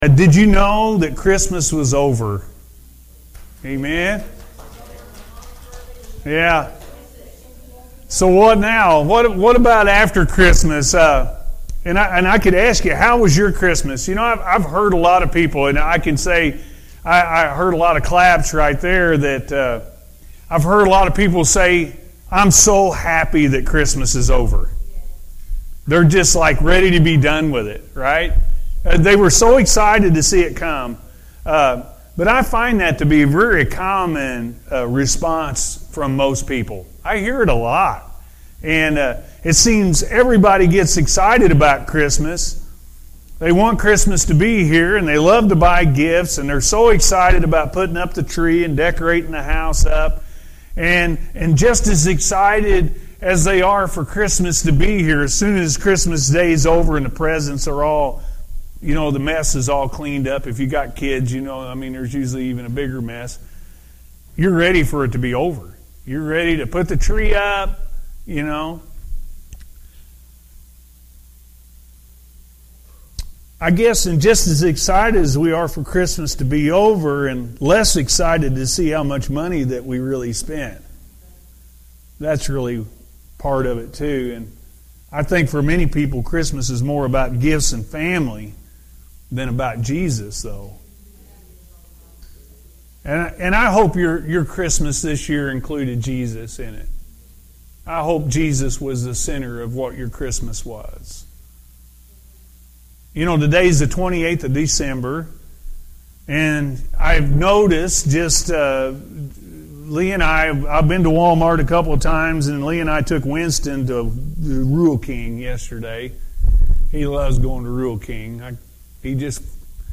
When God Calls, He Provides-A.M. Service